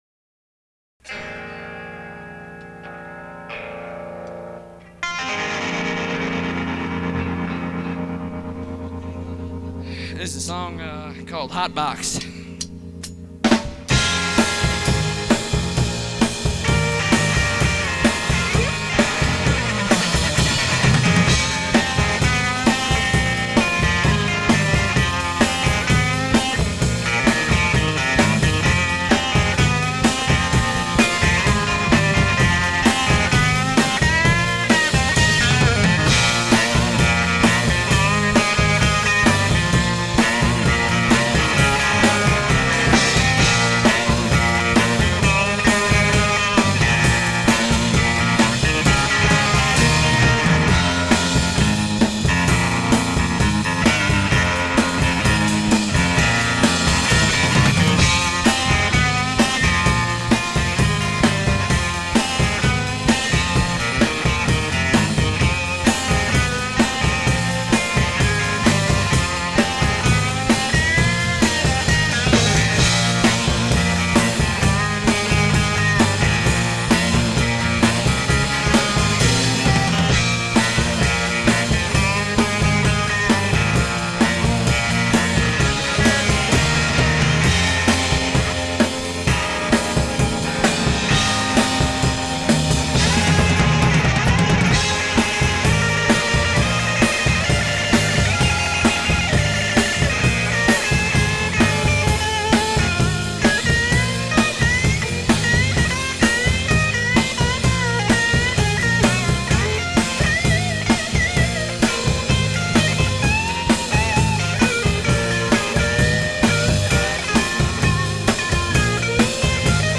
Tag: Alt-Country